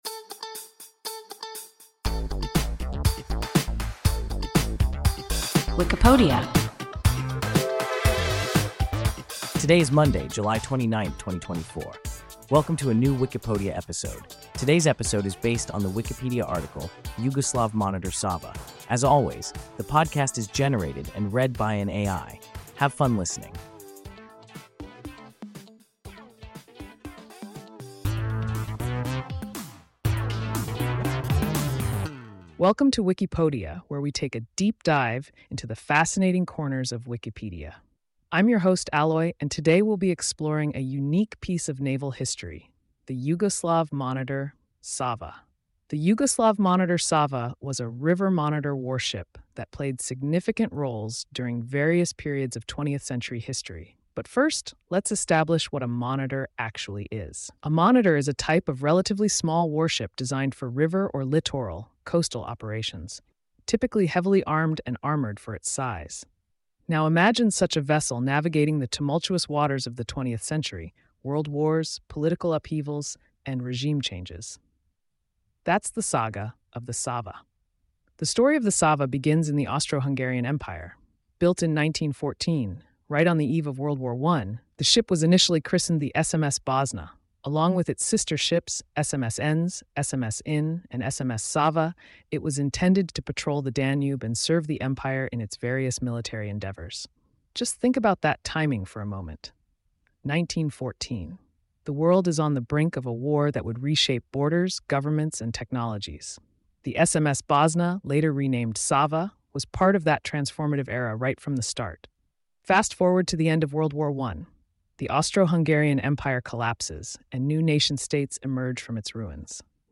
Yugoslav monitor Sava – WIKIPODIA – ein KI Podcast